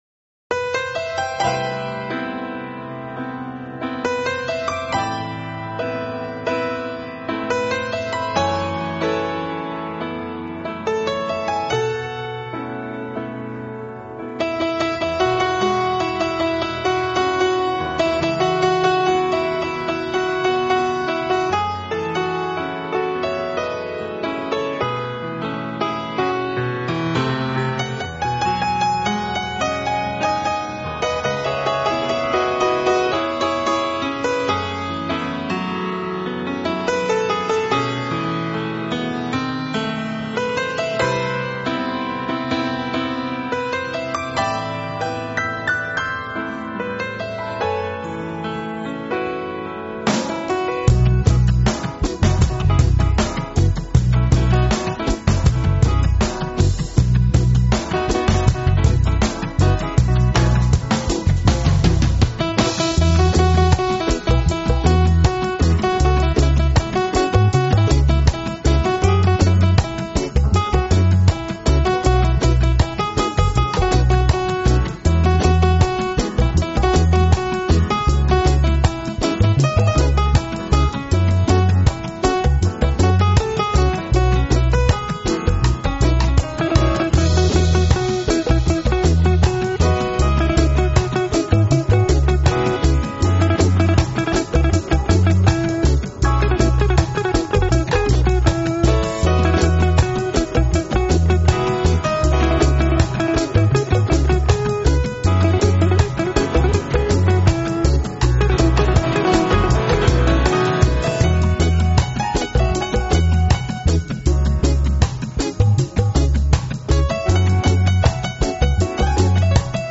大師的影響, 演奏以華麗豐厚為主要特色。
piano, melodica
Studios Aquarius, Geneva, Switzerland on July 17-18, 1995.